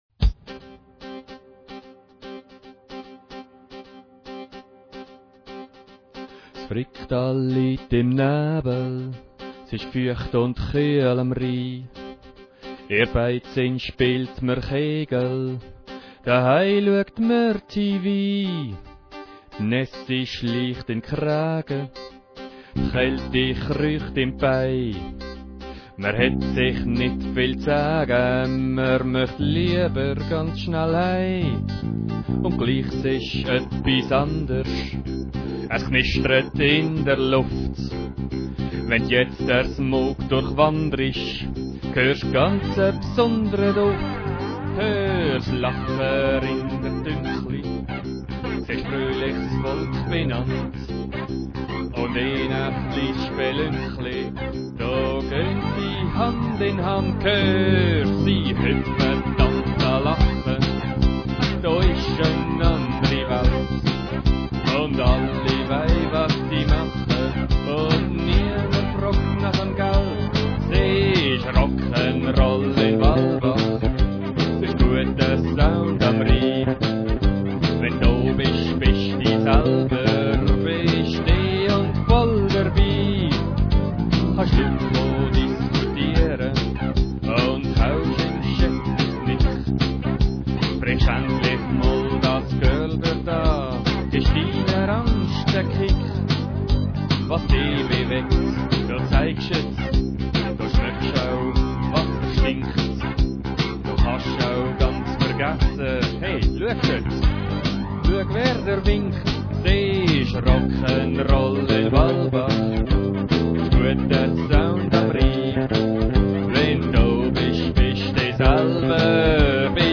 Es ist zwar mehr ein Scherzartikel. Ausser dem Schlagzeug, welches aus dem Computer stammt, habe ich alles selber eingespielt - zugegeben, mehr schlecht als recht.